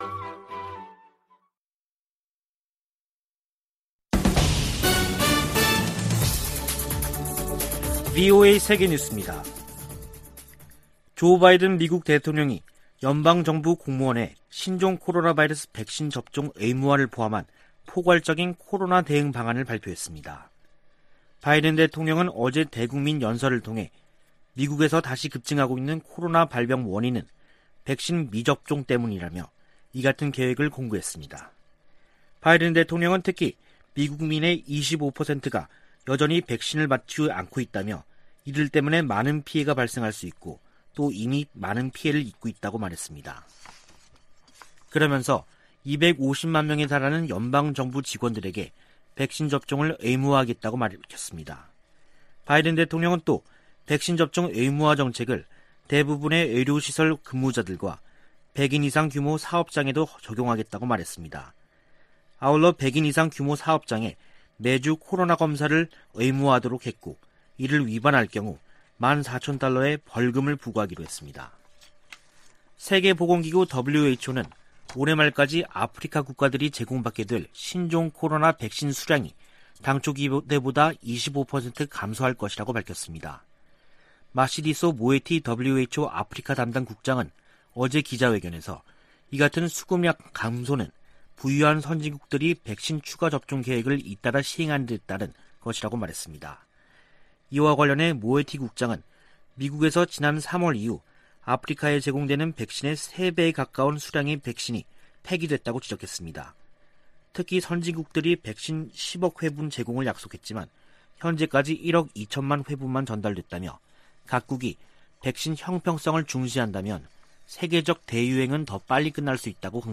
VOA 한국어 간판 뉴스 프로그램 '뉴스 투데이', 2021년 9월 10일 2부 방송입니다. 미국 정부는 한반도의 완전한 비핵화가 여전히 목표이며, 이를 위해 북한과의 외교가 중요하다고 밝혔습니다. 북한의 정권수립 73주년 열병식은 군사 보다는 경제 분야에 집중됐다고 미국의 전문가들이 분석했습니다. 중국과 북한의 강한 신종 코로나바이러스 대응 조치 때문에 탈북 네트워크가 거의 와해 수준인 것으로 알려졌습니다.